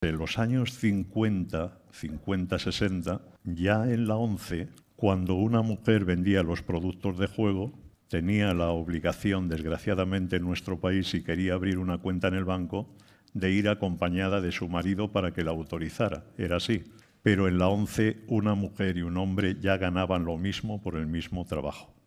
En una conferencia organizada por Fórum Europa pasa revista a la actualidad social y reclama "respeto y reconocimiento" para las organizaciones de la sociedad civil